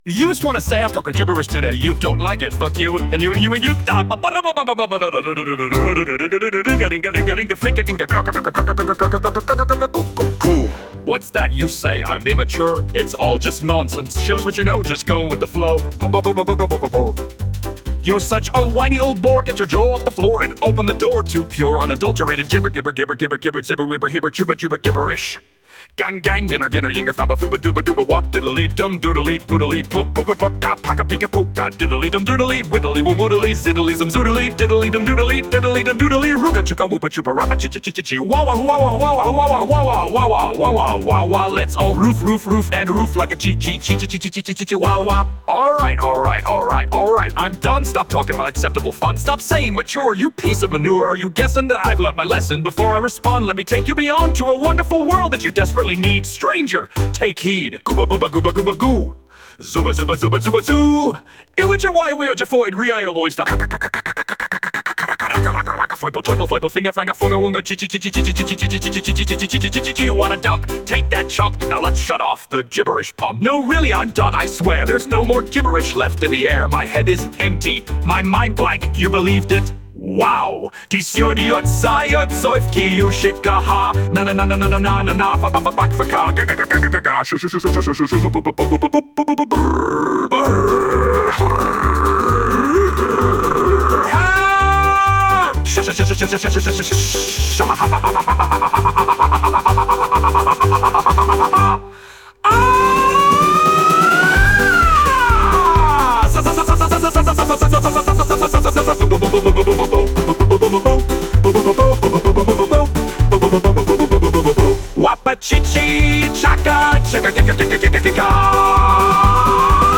Answer, write a whole bunch of ridiculous gibberish lyrics by mashing letters on the keyboard with a few invented words, chuck the whole thing into suno AI, tell the engine that you want comedy as a musical style and sit back and see what it does.